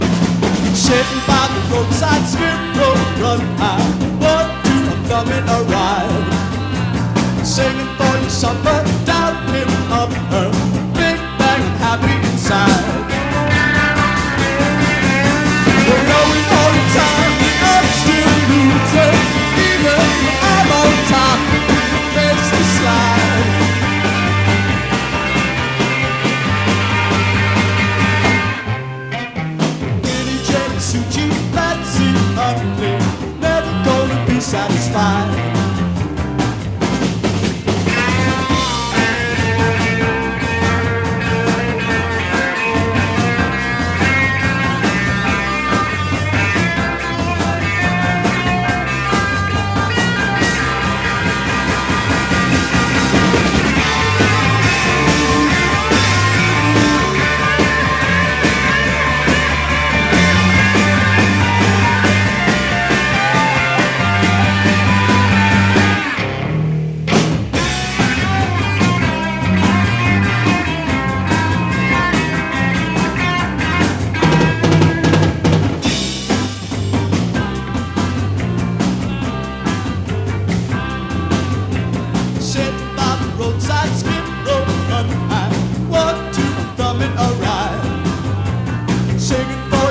ROCK / 60'S / BLUES ROCK / FOLK ROCK